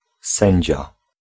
Ääntäminen
Ääntäminen France: IPA: [ʒyʒ] Haettu sana löytyi näillä lähdekielillä: ranska Käännös Ääninäyte Substantiivit 1. sędzia {m} 2. sędzina Suku: m .